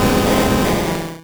Cri d'Onix dans Pokémon Rouge et Bleu.